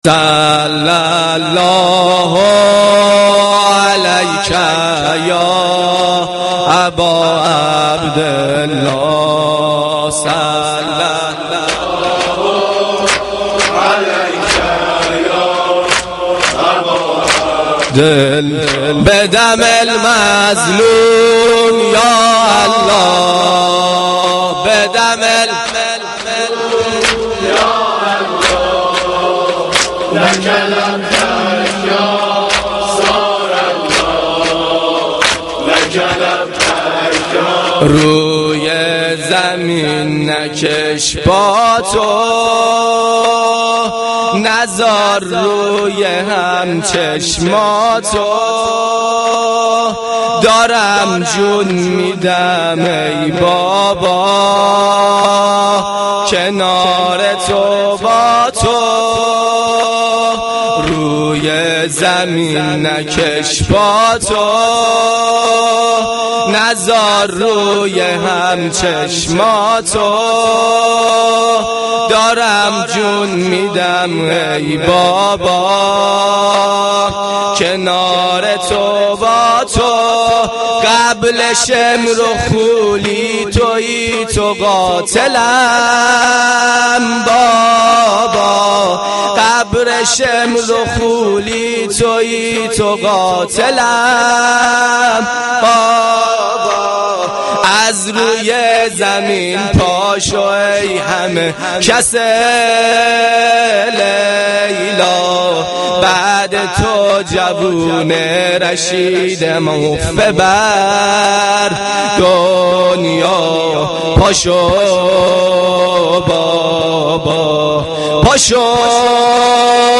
مداحی
Shab-8-Moharam-3.mp3